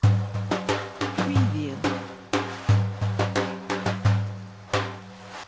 سمپل ریتم دف | دانلود لوپ دف با کیفیت بالا
سمپل ریتم دف | بدون نیاز به نوازنده دف به راحتی از ریتم های دف استفاده کنید که از ساز زنده توسط بهترین نوازنده در بهترین استودیو ضبط شده
demo-daf.mp3